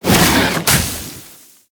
Sfx_creature_snowstalkerbaby_flinch_land_03.ogg